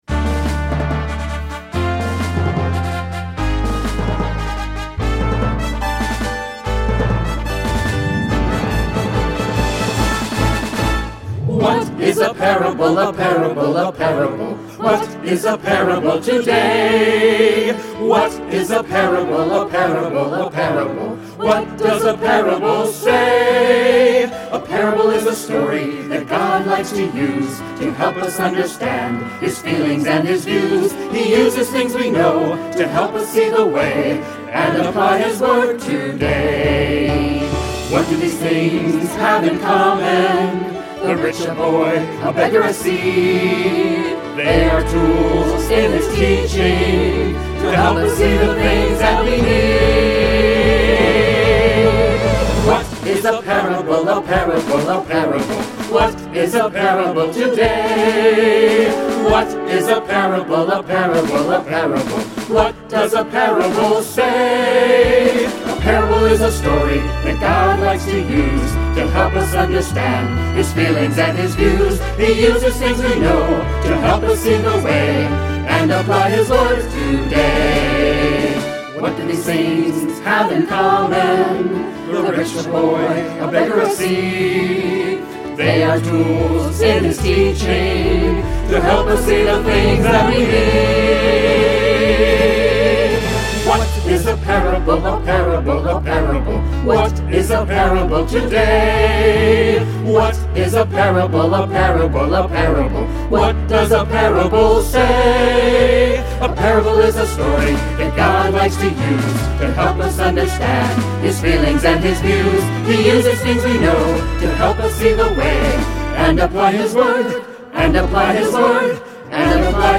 BONUS SECTION (VOCAL MUSIC TRACKS)